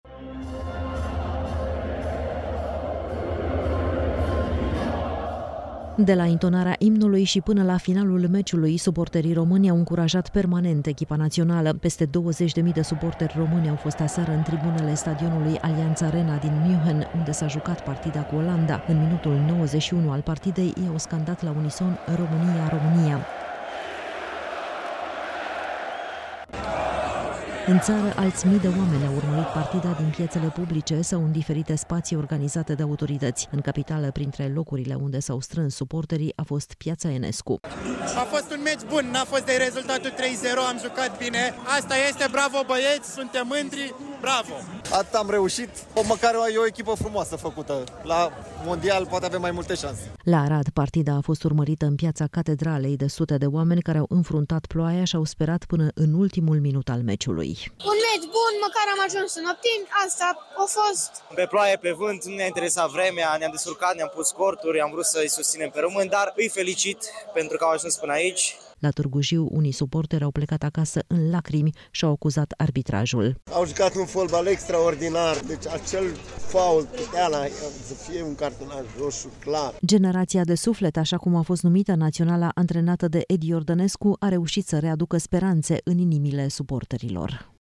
De la intonarea imnului și până la finalul meciului, suporterii români au încurajat permanent echipa națională.
Peste 20.000 de suporteri români au fost marți seară în tribunele stadionului Allianz Arena din Munchen, unde s-a jucat partida cu Olanda.
În minutul 91 al partidei, ei au scandat la unison, „România, România!”